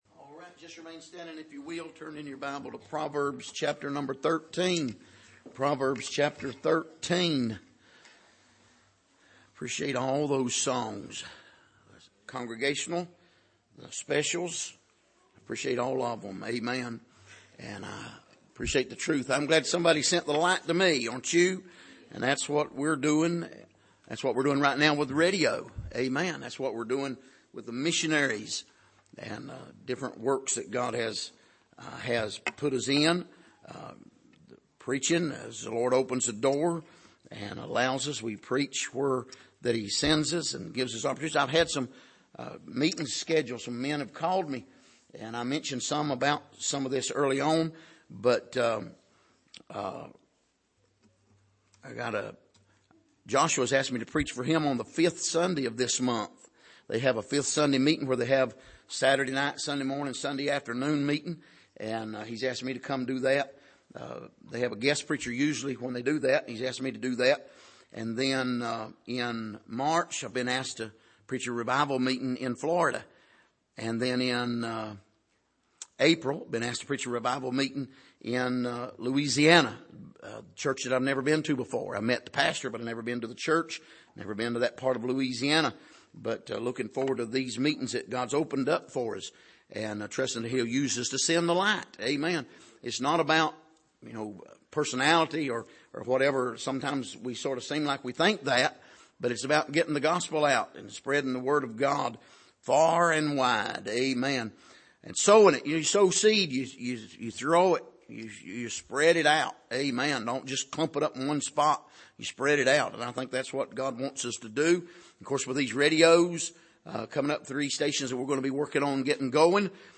Passage: Proverbs 13:18-25 Service: Sunday Evening